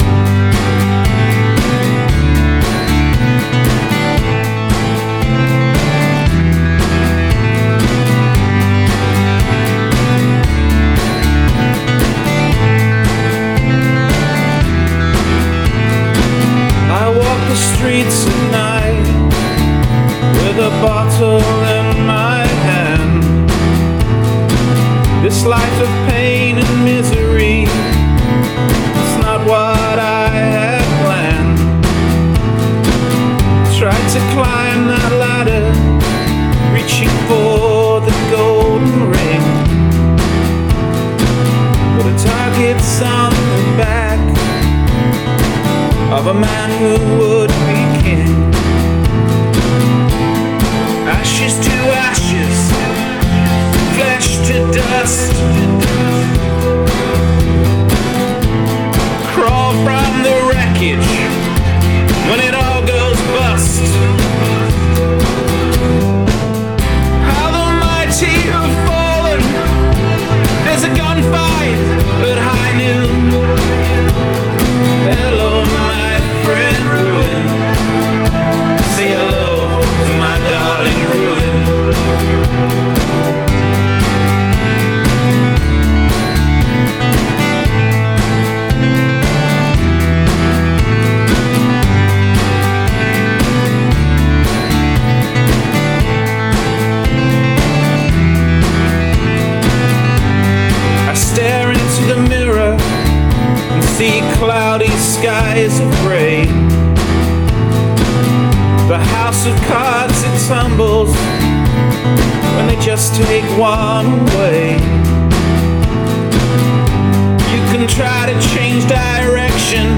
Vocals seemed a little bit guilty of pitch problems.